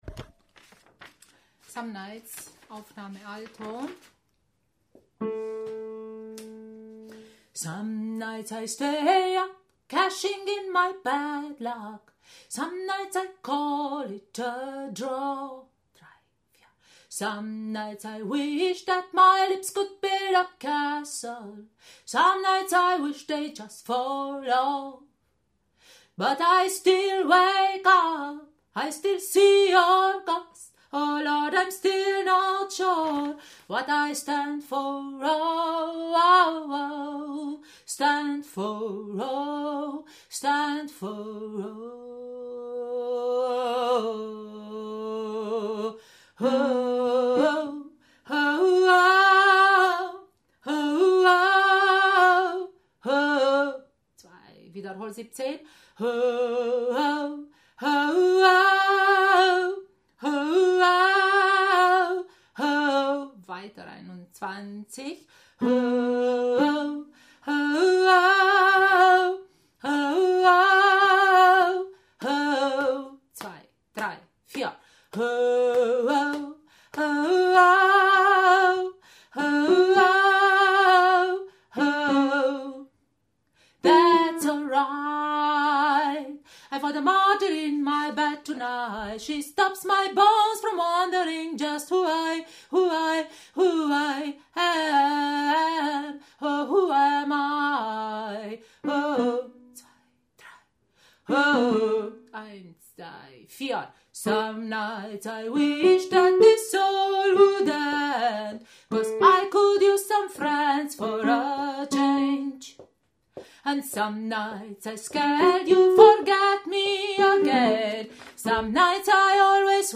Some Nights – Alto